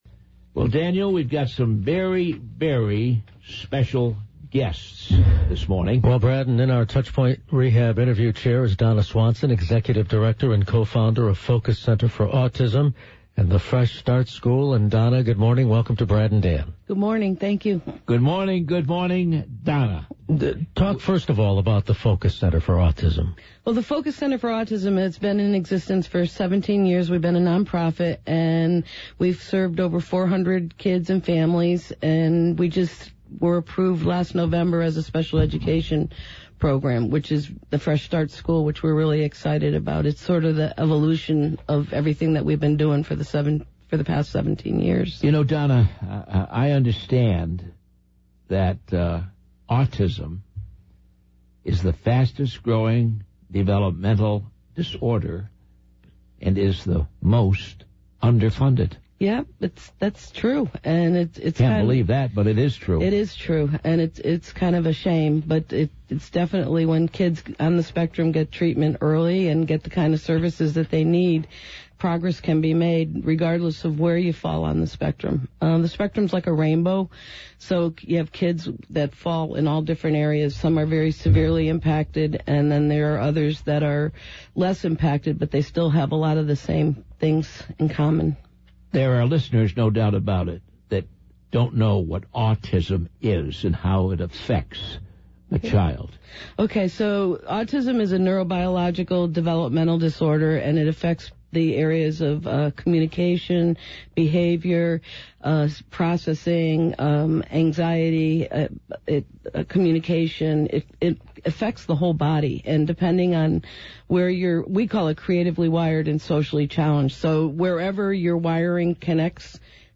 discusses the school and its goals in this interview.